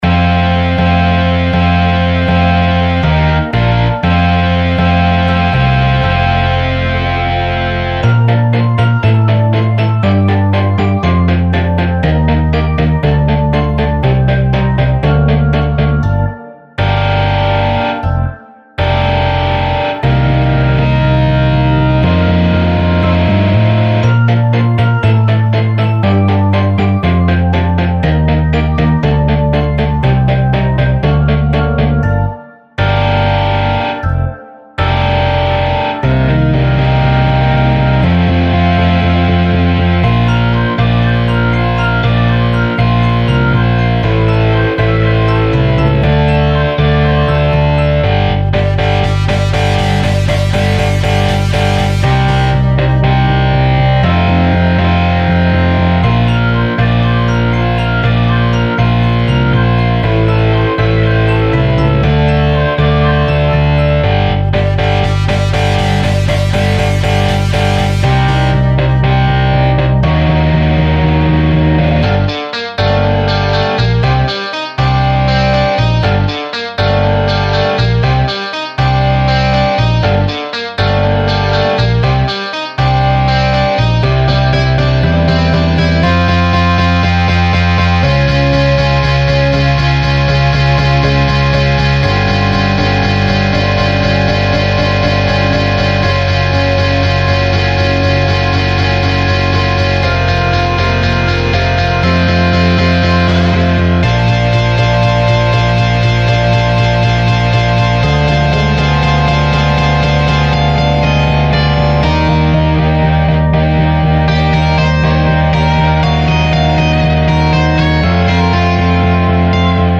●AmpRoomRock●StudiumRock●Chunk2